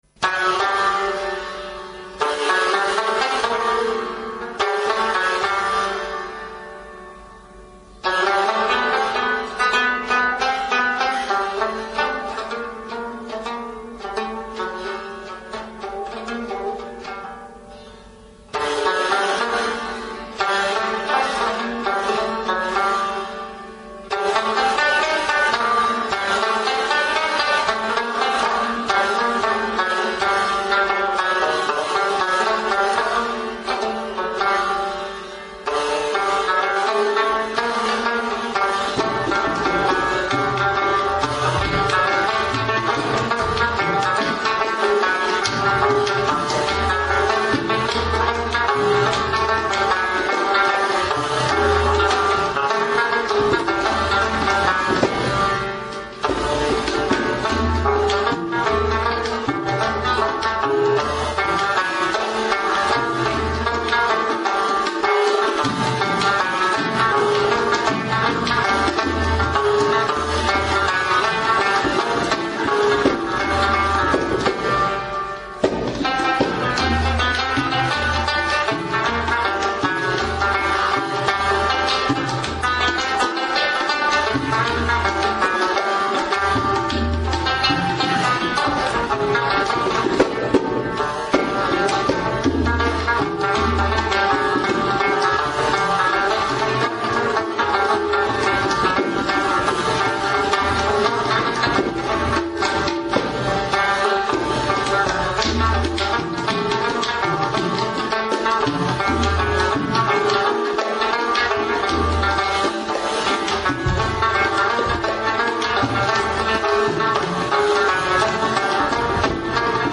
rabab
tabla
Its neck and body are carved from a single piece of hollowed wood.